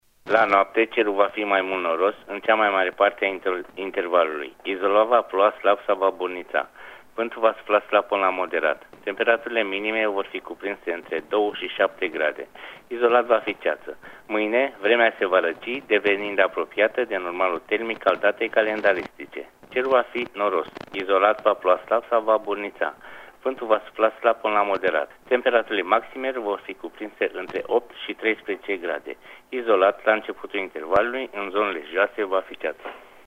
Prognoza meteo 12/13 noiembrie (audio)